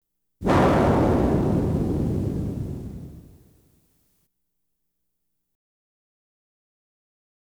Explosive Space Launch Sound Effect
Download a high-quality explosive space launch sound effect.
explosive-space-launch.wav